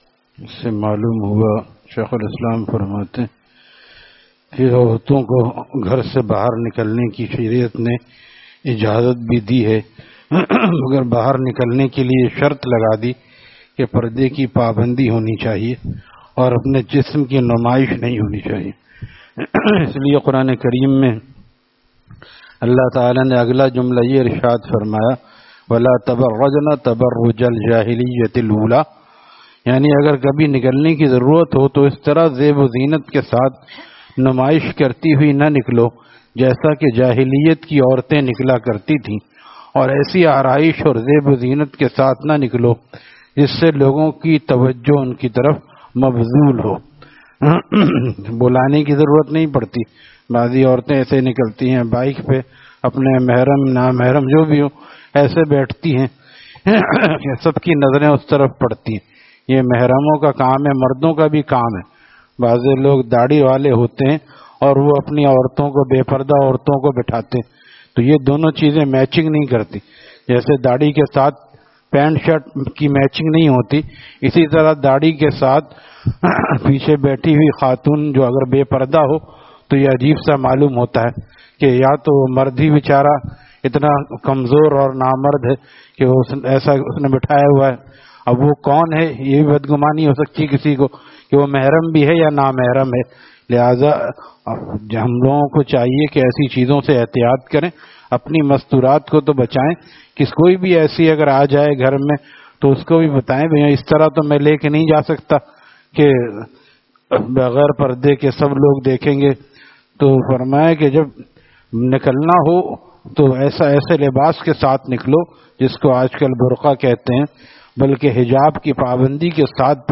Taleem After Fajar at Jamia Masjid Gulzar e Mohammadi, Khanqah Gulzar e Akhter, Sec 4D, Surjani Town